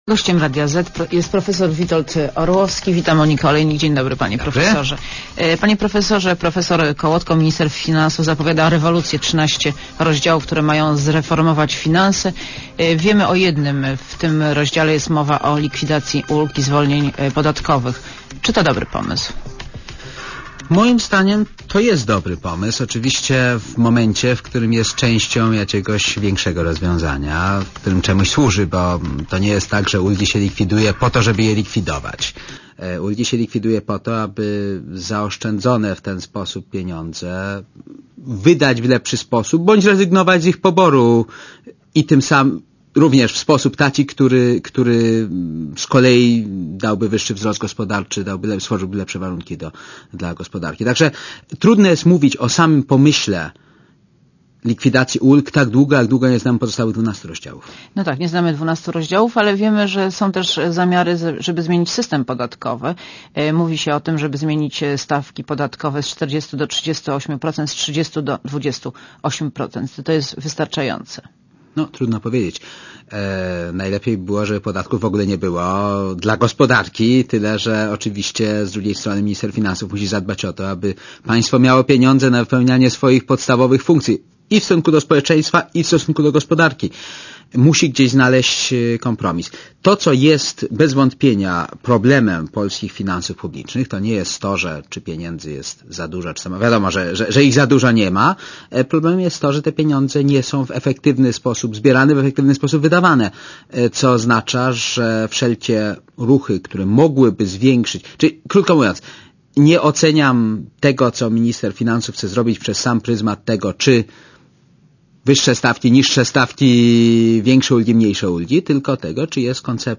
Monika Olejnik rozmawia z profesorem Witoldem Orłowskim - doradcą ekonomicznym prezydenta